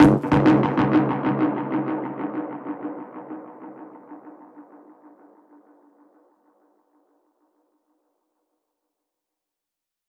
DPFX_PercHit_C_95-09.wav